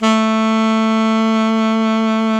SAX ALTOMP04.wav